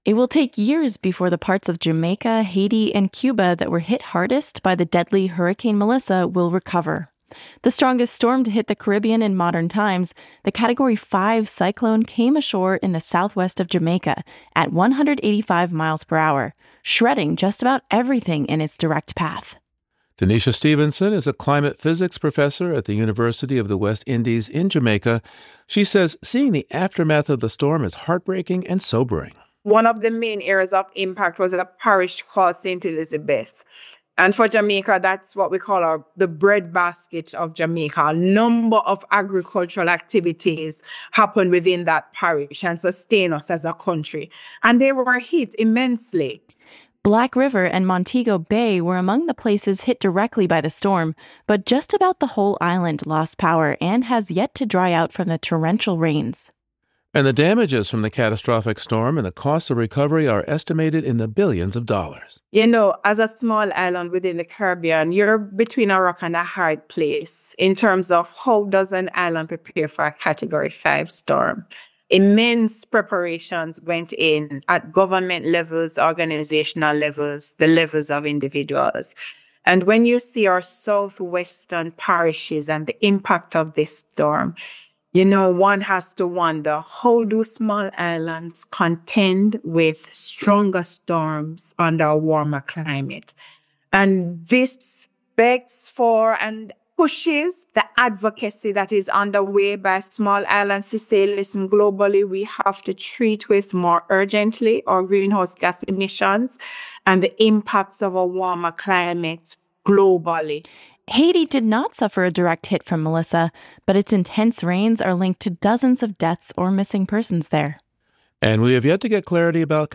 Hurricane Melissa, the strongest storm to hit the Caribbean in modern times, left a wake of destruction in Jamaica, Cuba and Haiti that will take years to recover from. A Jamaican climate physics professor describes the toll of this climate catastrophe, and a meteorologist joins us to explain how the storm grew so ferocious in the blink of a hurricane’s eye.